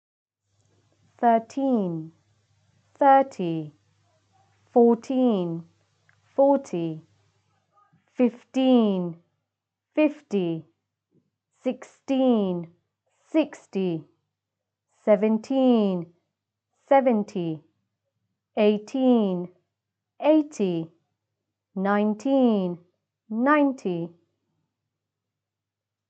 Ask the learners if they can hear the difference (between the following numbers: thirteen – thirty, fourteen – forty, fifteen – fifty, sixteen – sixty, seventeen – seventy, eighteen – eighty, nineteen – ninety).